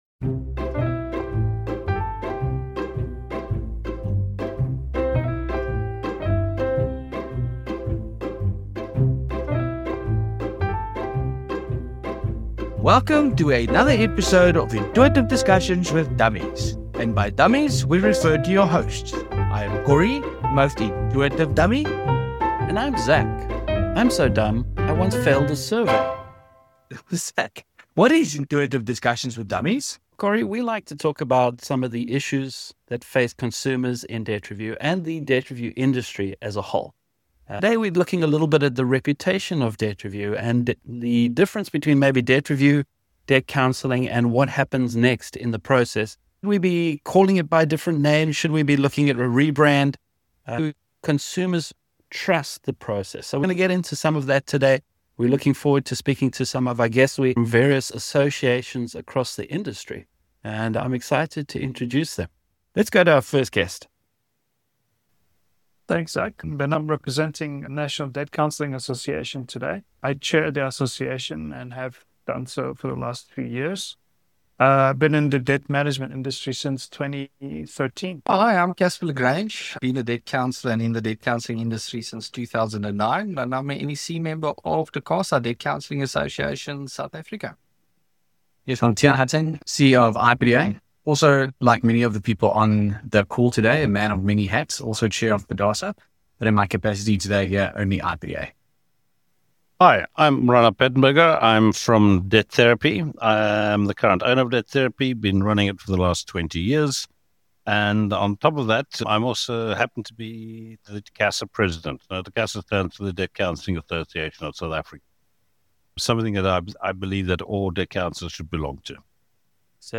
Debt Review With Dummies Intuitive Discussions (Episode 2)
The Dummies get to speak to special guests